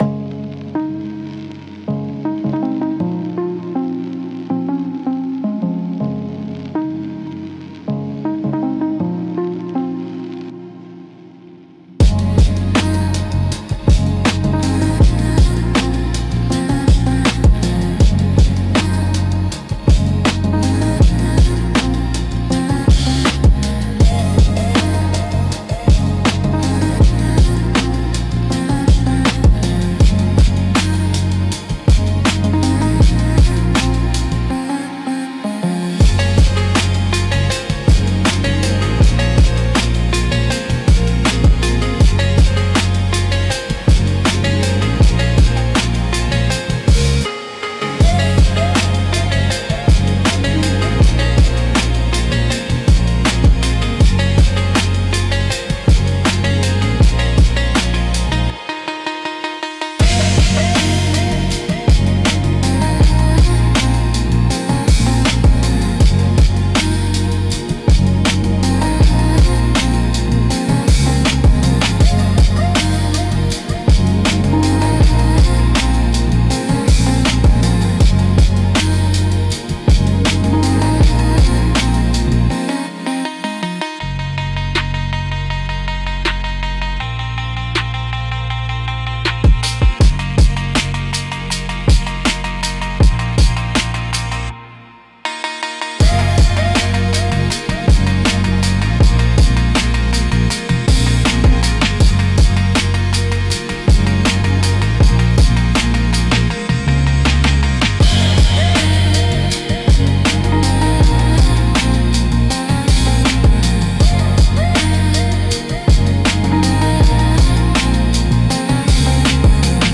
Genre: Lofi Mood: Peaceful Editor's Choice